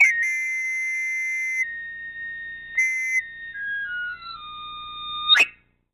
Una flauta callejera del presente resistente y una Cantante Ambulante del pasado perdida en la época de la Lira Italiana se encuentran, coinciden en Mib menor Dórico afinados a 432Hz y generan las 2 melodías temáticas (fragmentos temáticos rudimental)
Flauta-de-afilador-B.wav